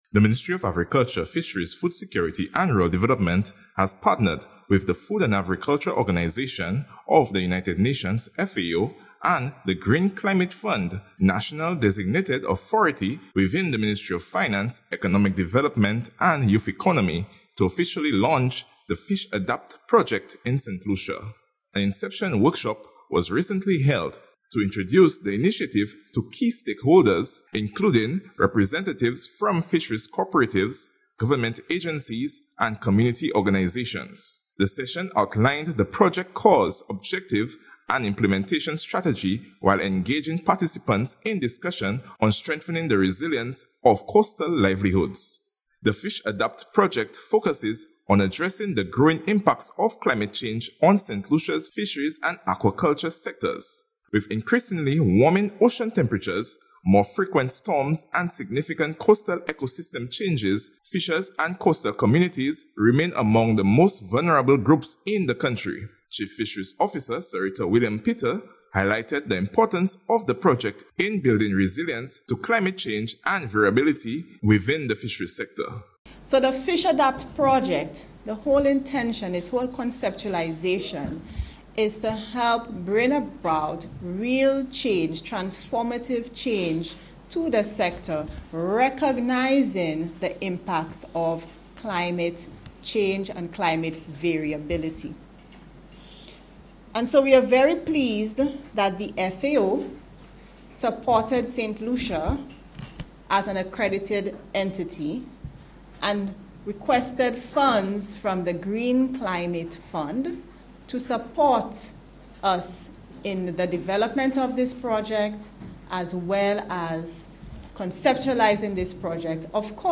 At an inception workshop with fisheries cooperatives, government agencies, and community partners, stakeholders were introduced to the project’s objectives and implementation plan, emphasizing the urgent need to safeguard marine-based livelihoods.
Inception-workshop-for-the-FISH-ADAPT-Project-ANR.ogg